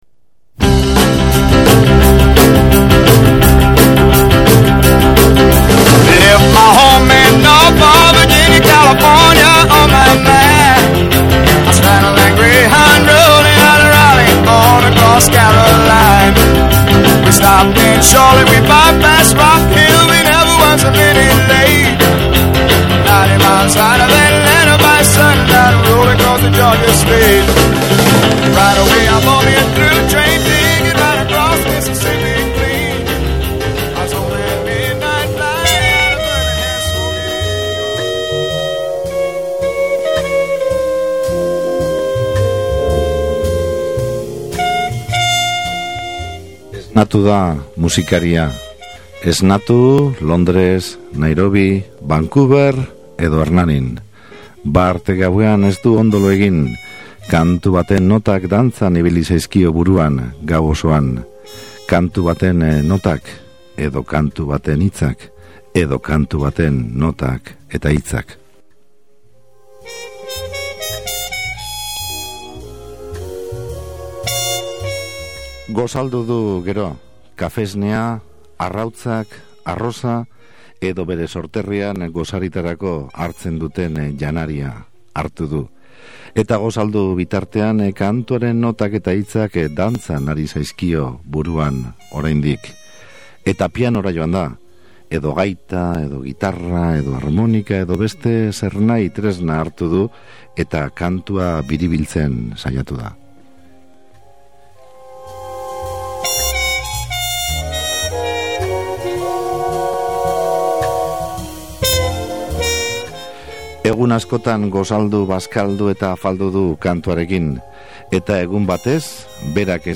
Zuzeneko musika eduki dugu gaurko Soinugelan
Egia esan, primiziatzat har genezake bisita hau, oraindik grabatzeke dauzkan hiru kantu eskaini baitizkie zuzenean gure entzuleei.